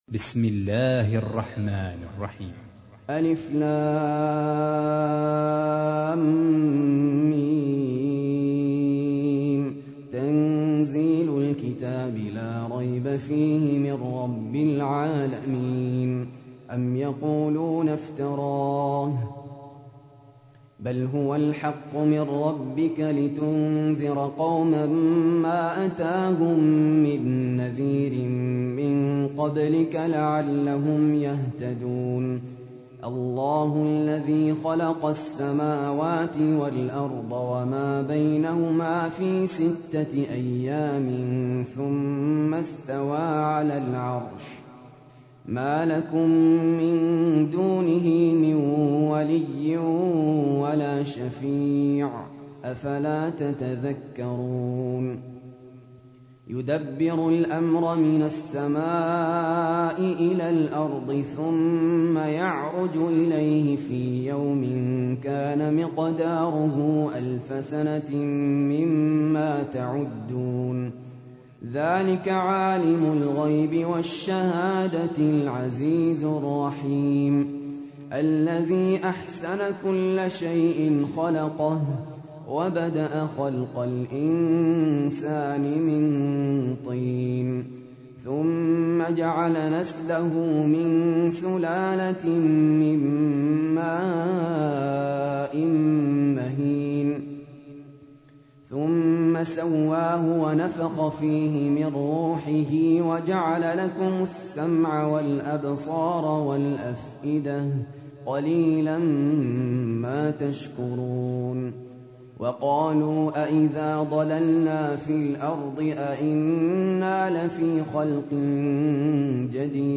تحميل : 32. سورة السجدة / القارئ سهل ياسين / القرآن الكريم / موقع يا حسين